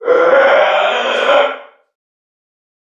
NPC_Creatures_Vocalisations_Puppet#36 (search_01).wav